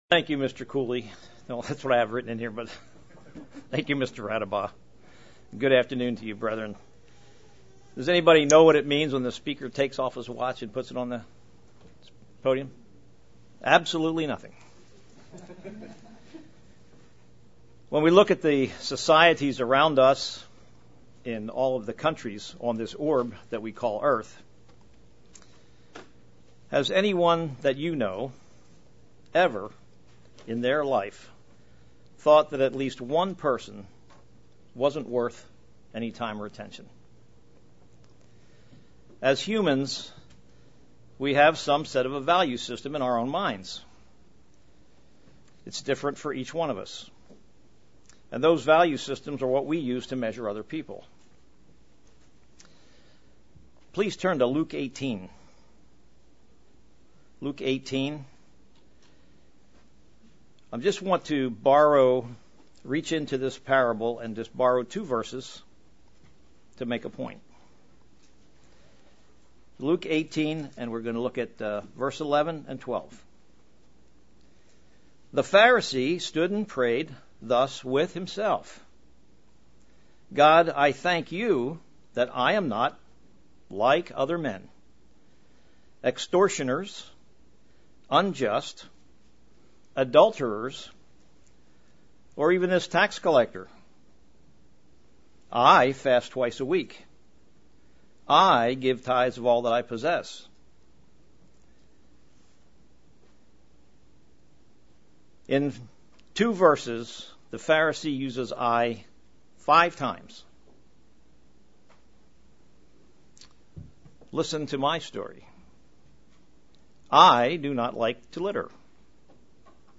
An excellent sermon on the value of a Christian.